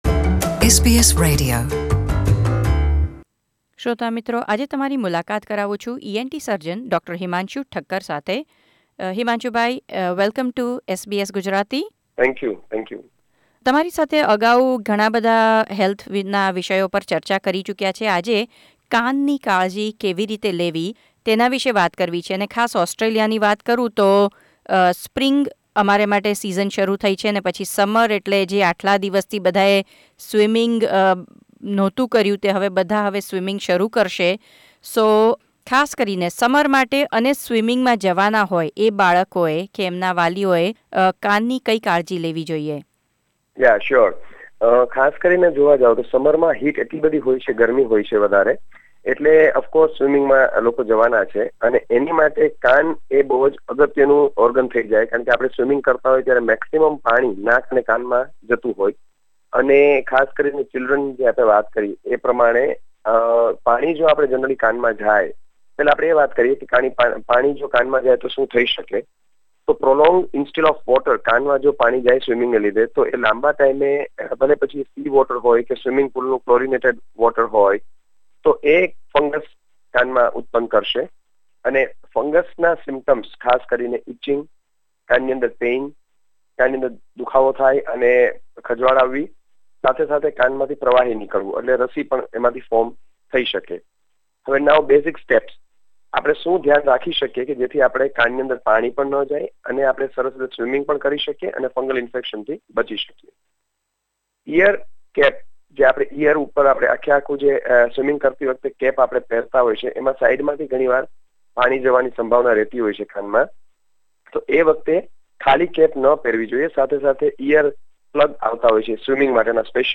ENT Surgeon